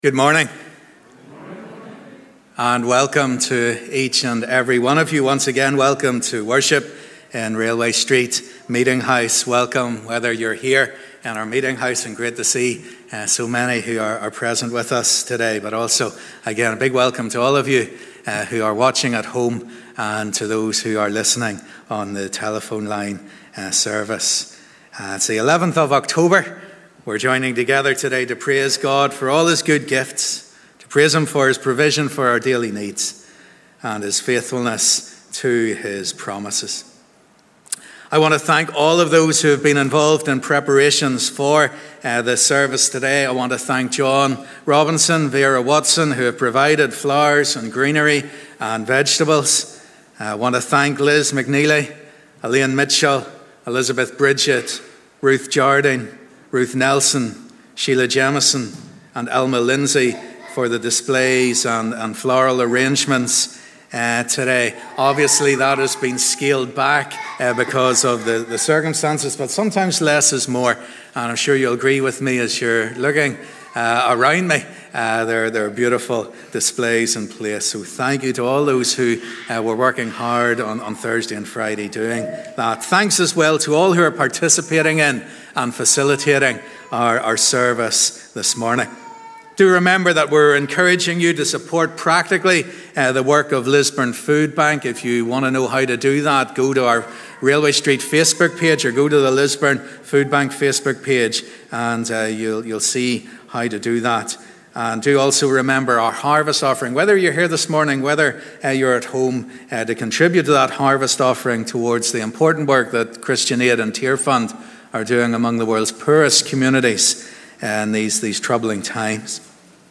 Sunday 11th October 2020 Live @ 10:30am Harvest Thanksgiving Family Service Audio will be available after the service.